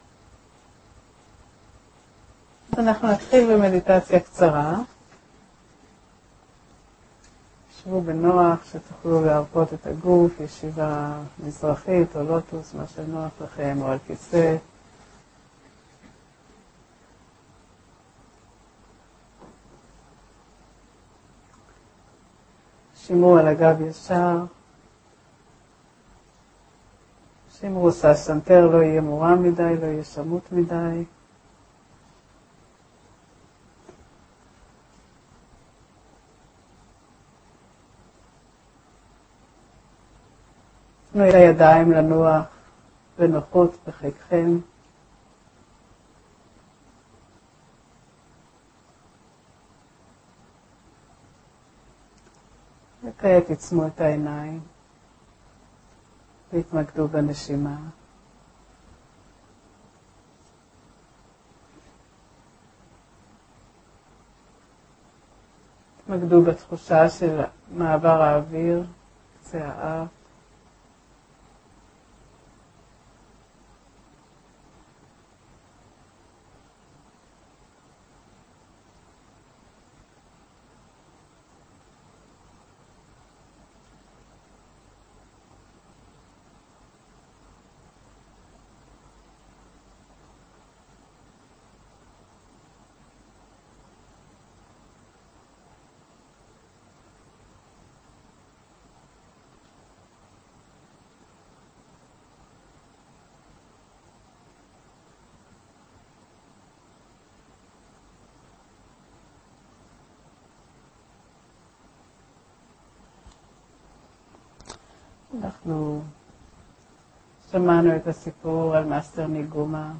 הקלטות מסדנה